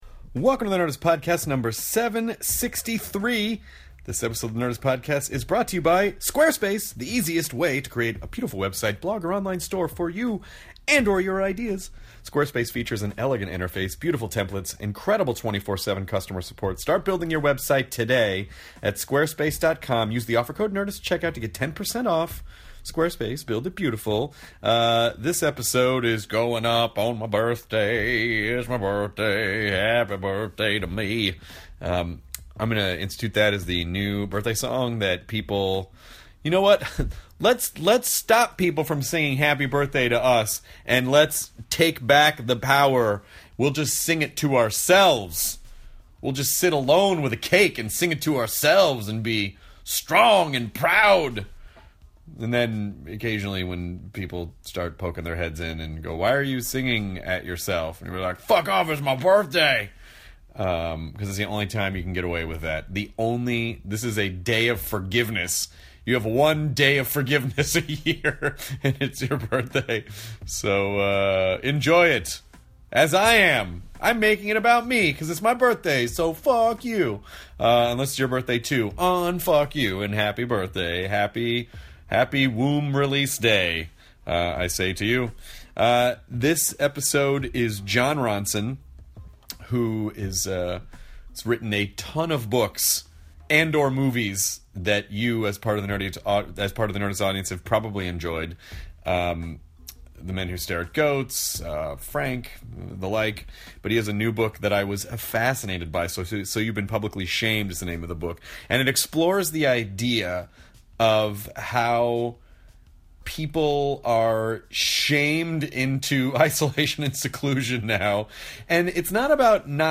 Jon Ronson (author, So You’ve Been Publicy Shamed) chats with Chris about why today’s society loves to publicly shame people, how shaming differs between men and women, the importance of knowing all the facts before forming opinions and his book So You’ve Been Publicy Shamed!